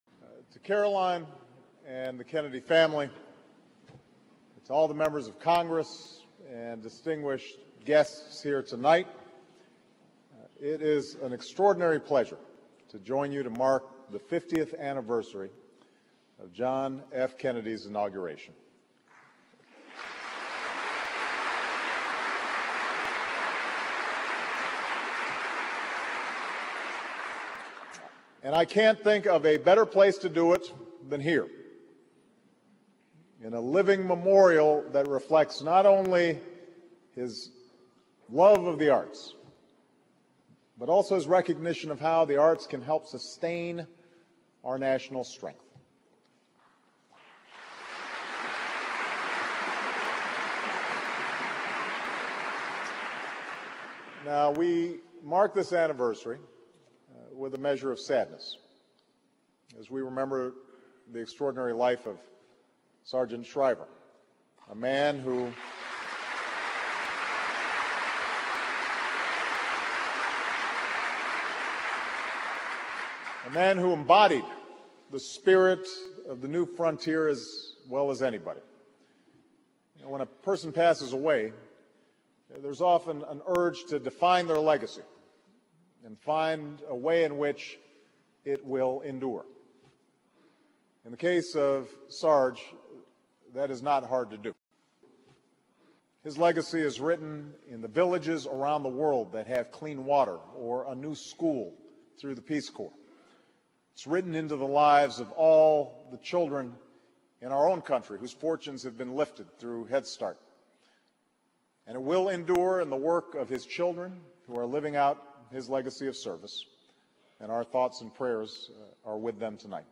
U.S. President Barack Obama speaks at the 50th anniversary of John F. Kennedy's inauguration